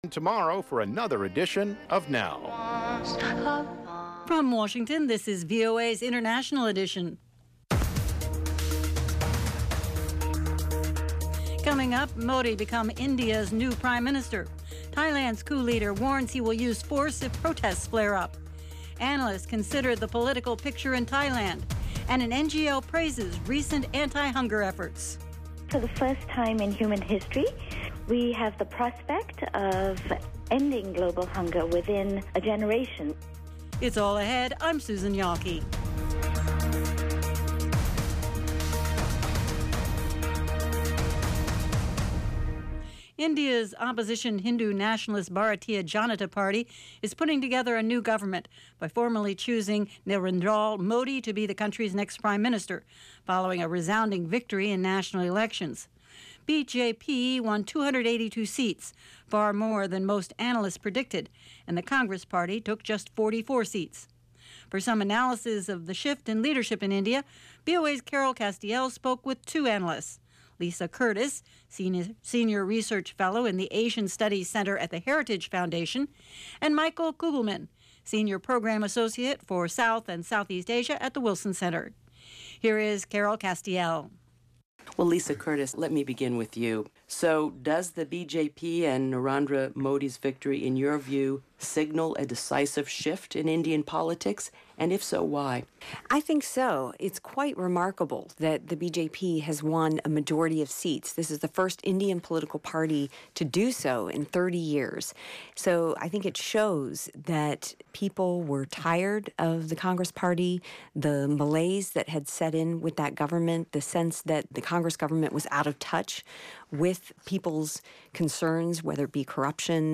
International Edition gives you 30 minutes of in-depth world news reported by VOA’s worldwide corps of correspondents - on the events people are talking about.